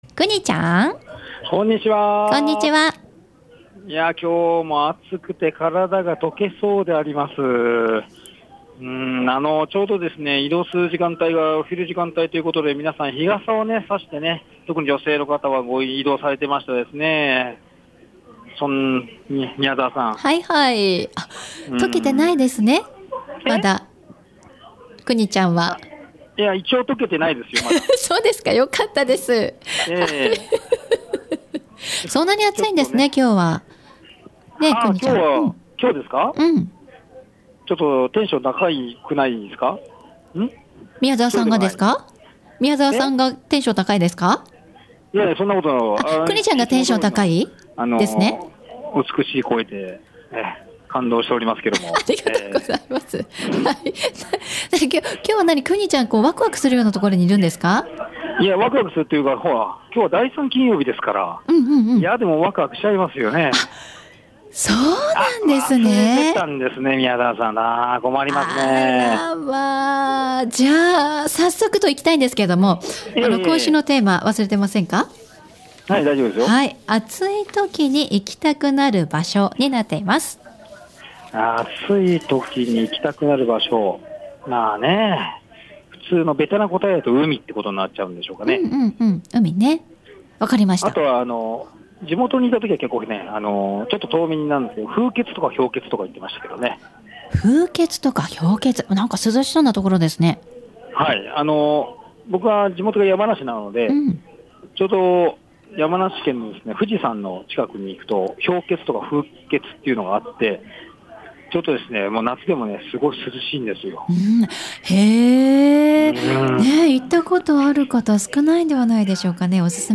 午後のカフェテラス 街角レポート
本当に駅の中央口から2分ほどにあります M’sKitchen（エムスキッチン）さんにお邪魔しました。